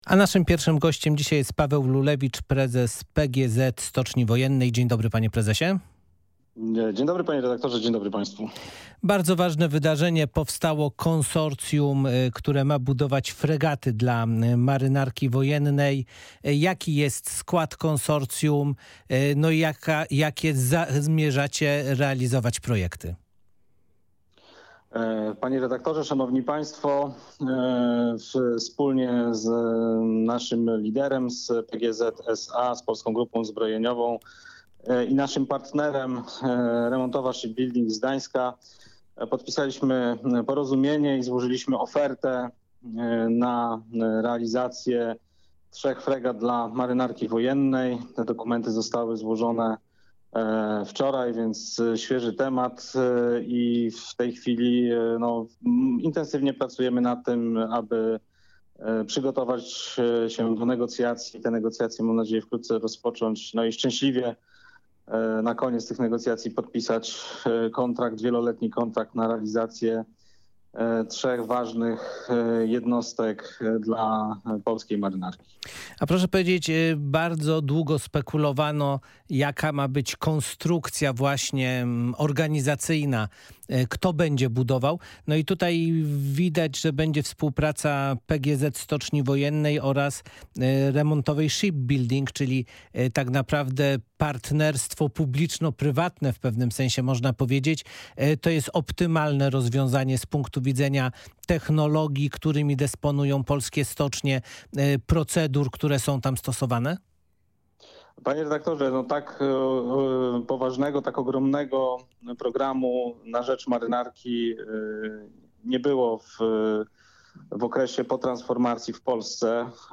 O projektach, które będzie realizowało konsorcjum, rozmawialiśmy w audycji „Ludzie i Pieniądze”.